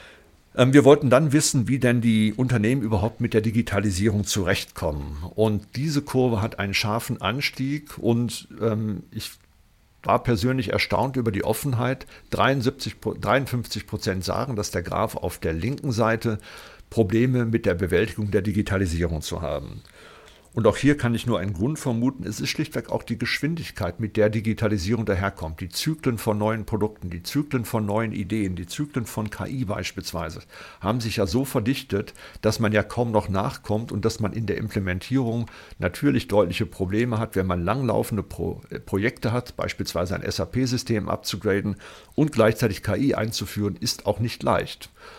Mitschnitte der Pressekonferenz
pressekonferenz-digitalisierung-der-wirtschaft-2025-wie-kommen-unternehmen-mit-digitalisierung-zurecht.mp3